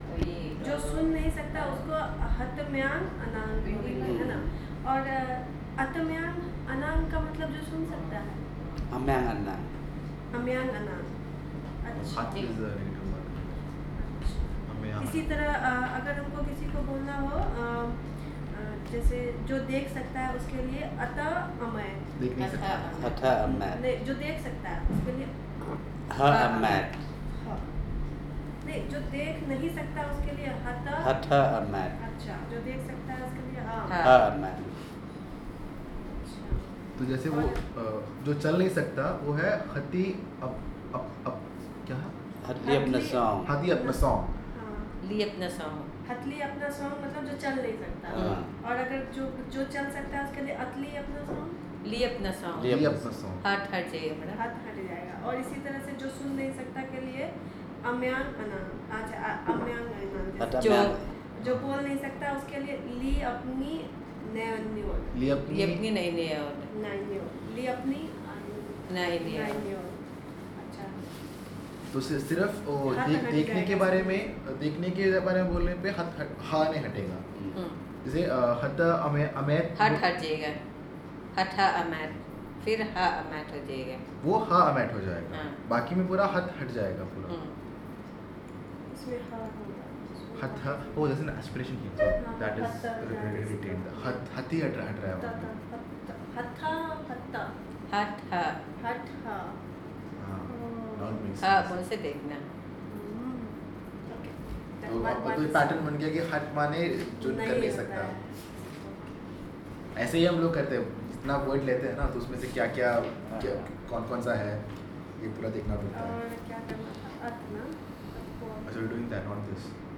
Elicitation of words and personal narrative on sociolinguistic information